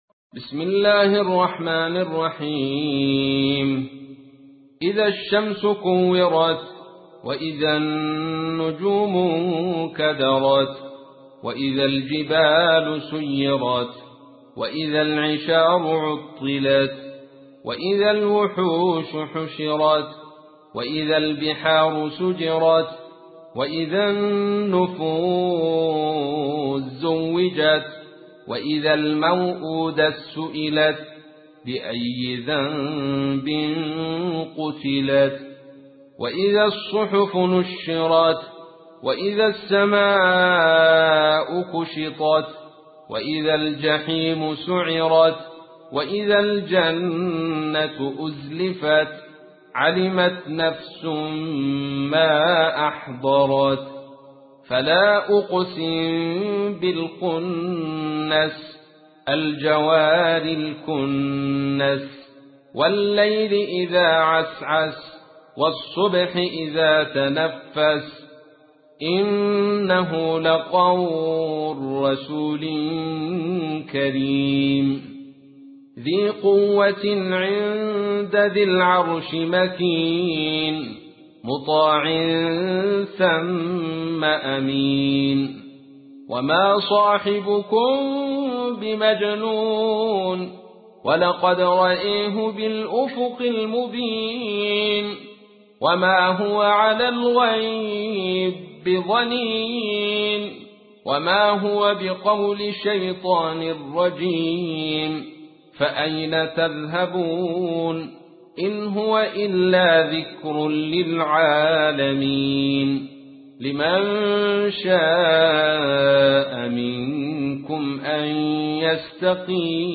تحميل : 81. سورة التكوير / القارئ عبد الرشيد صوفي / القرآن الكريم / موقع يا حسين